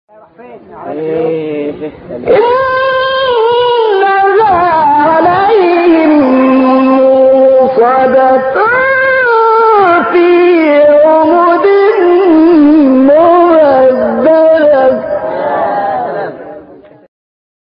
تلاوت 8-9 همزه استاد محمد عبدالعزیز حصان | نغمات قرآن
سوره : همزه آیه : 8-9 استاد : محمد عبدالعزیز حصان مقام : بیات روایت : شعبه / کسایی إِنَّهَا عَلَيْهِم مُّؤْصَدَةٌ ﴿٨﴾ فِي عَمَدٍ مُّمَدَّدَةٍ ﴿٩﴾ قبلی بعدی